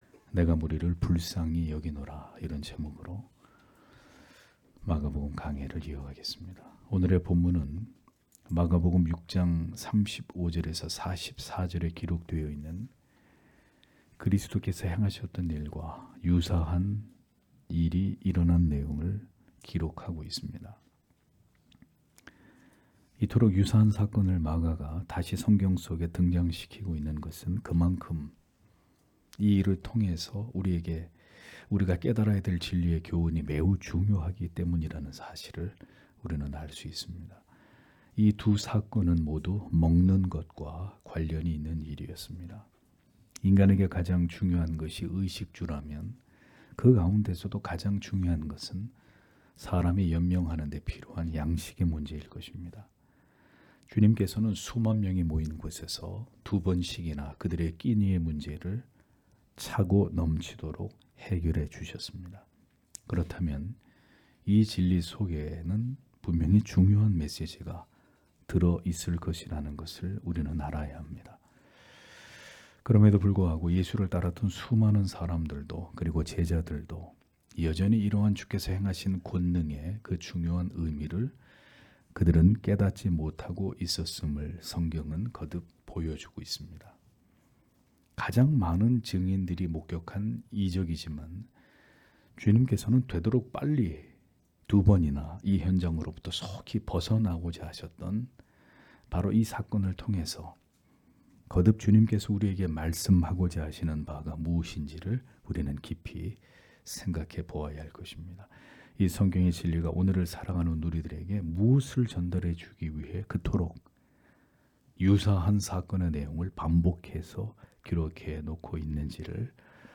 주일오전예배 - [마가복음 강해 29] 내가 무리를 불쌍히 여기노라 (막 8장 1-10절)